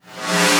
VEC3 Reverse FX
VEC3 FX Reverse 29.wav